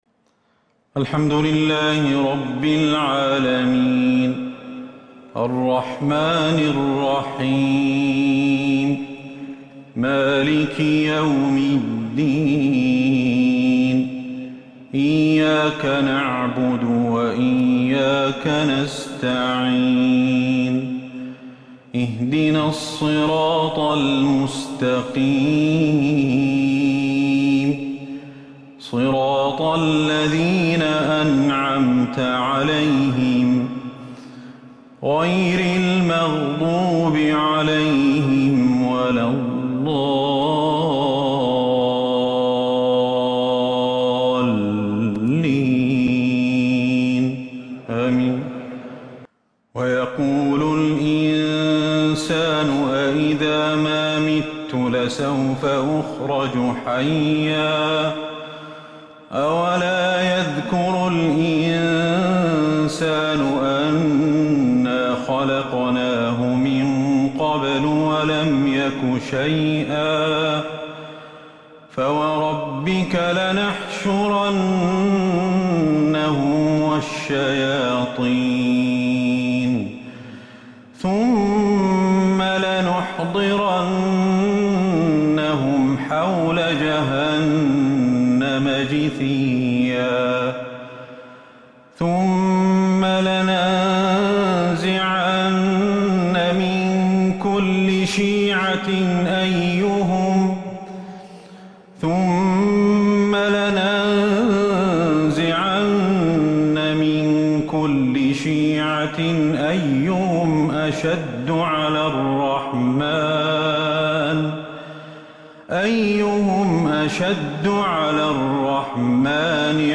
عشاء الإثنين 28 رمضان 1442 هـ ما تيسر من سورة {مريم} > 1442 هـ > الفروض - تلاوات الشيخ أحمد الحذيفي